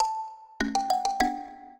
mbira
minuet13-4.wav